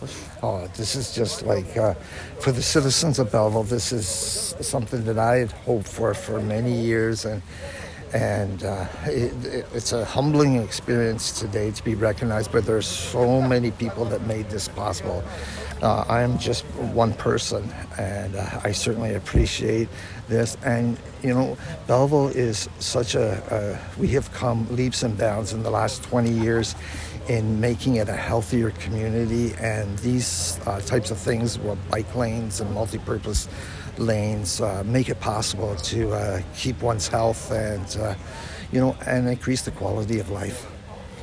Boyce credited his mother as his inspiration for the work he did on council and said he was honoured to have the trail named after him.